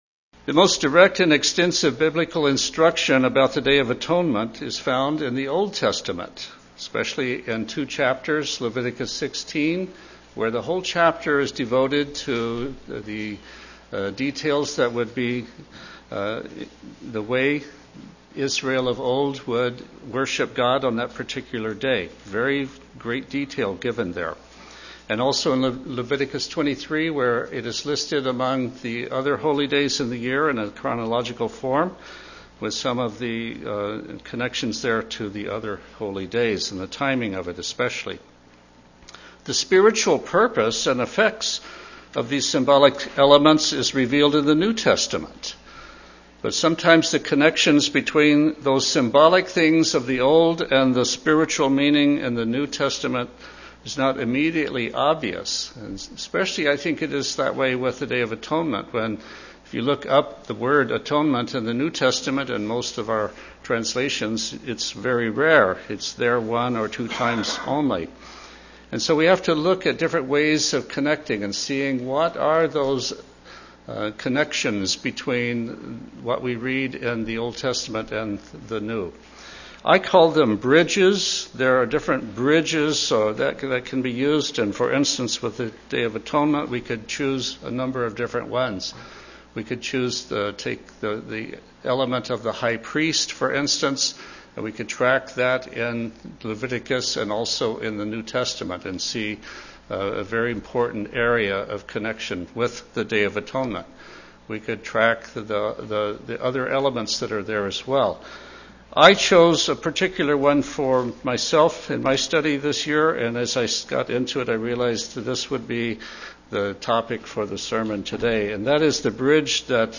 Sermons
Given in Olympia, WA